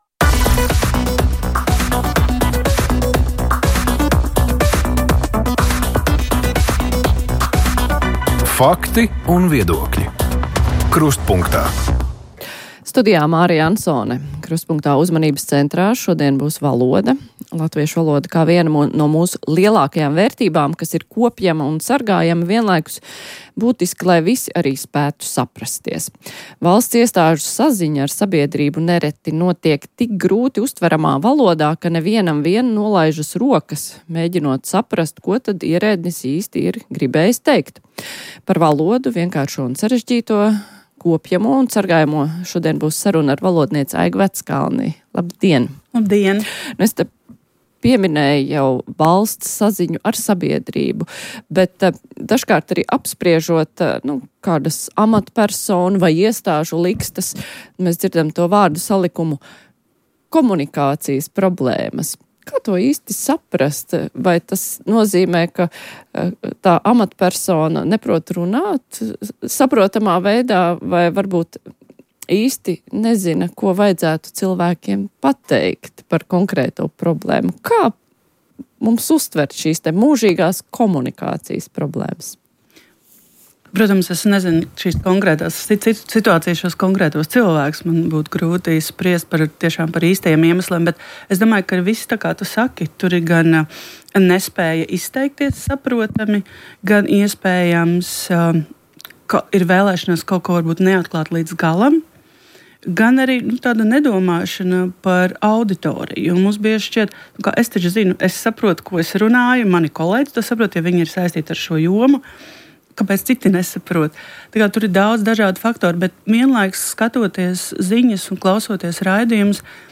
saruna ar valodnieci